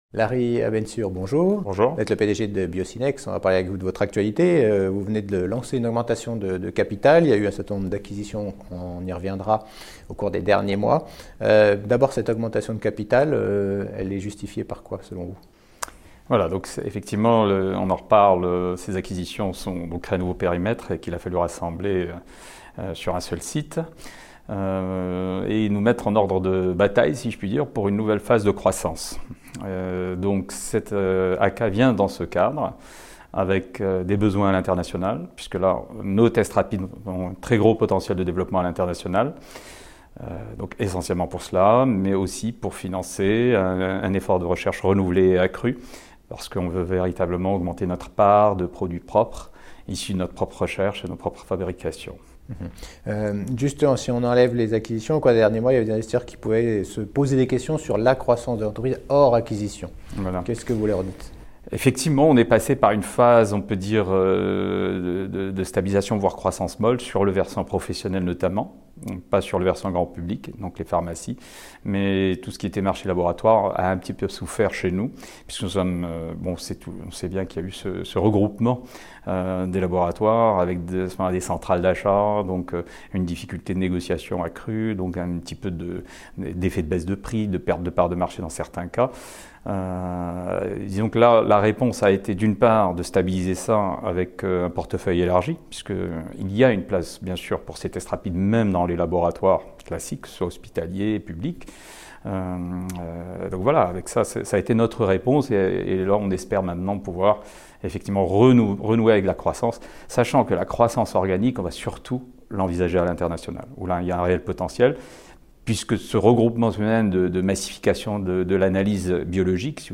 Category: L'INTERVIEW